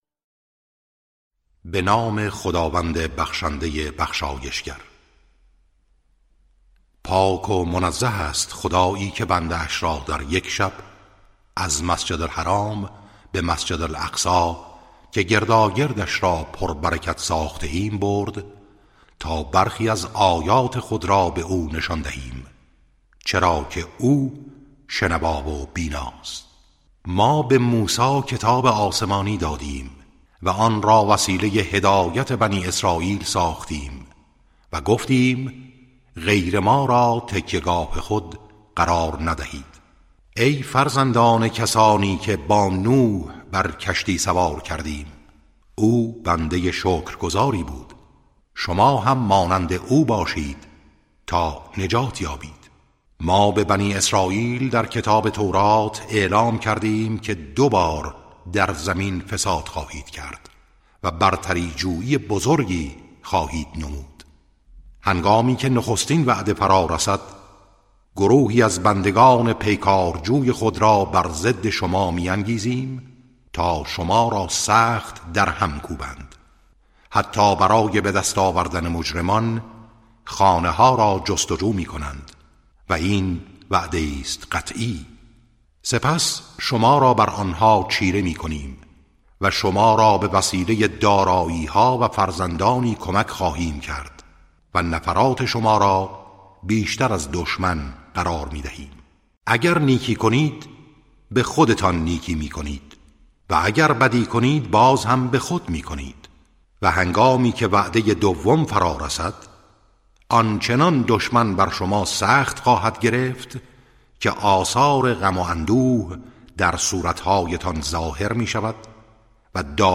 ترتیل صفحه ۲۸۲ از سوره اسراء(جزء پانزدهم)